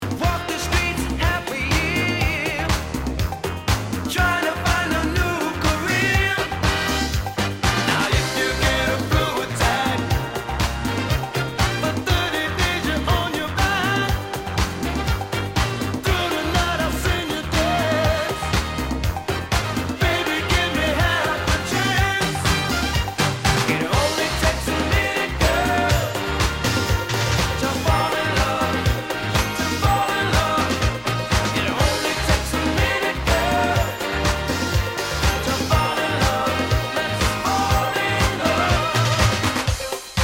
Tag       DANCE CLASSICS OTHER